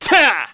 Neraxis-Vox_Attack4_kr.wav